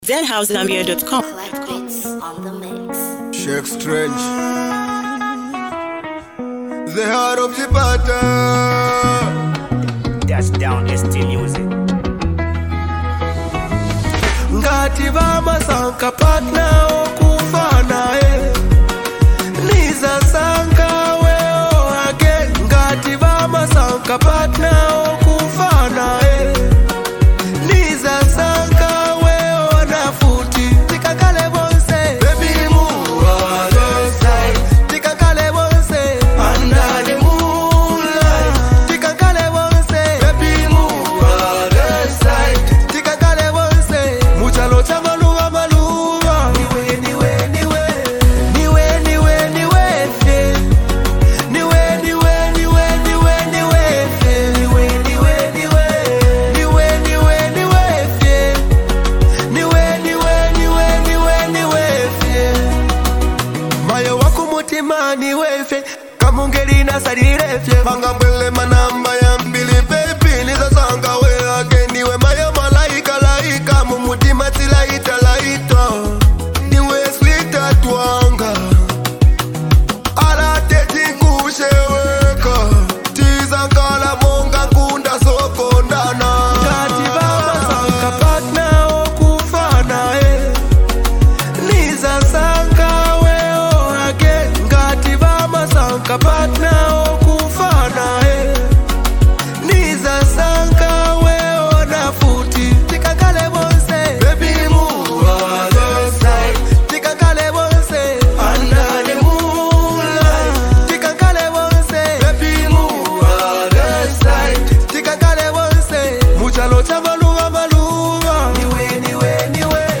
smooth vibes